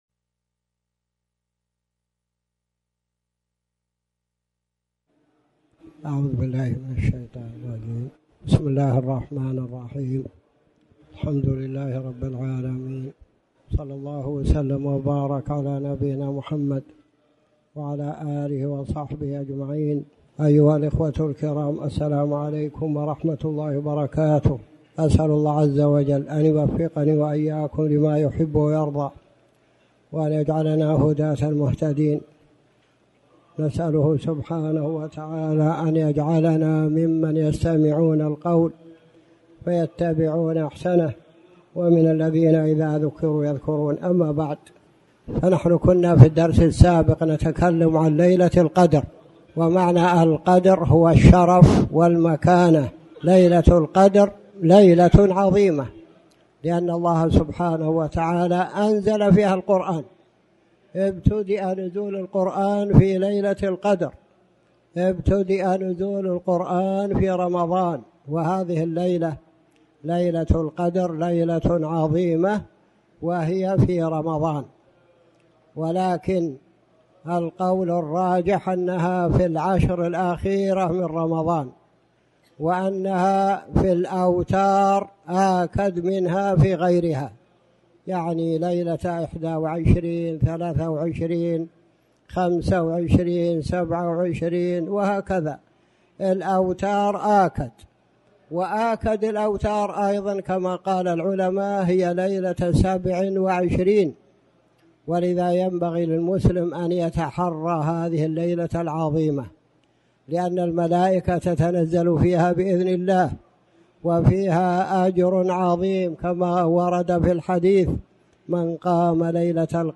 تاريخ النشر ١٨ شعبان ١٤٣٩ هـ المكان: المسجد الحرام الشيخ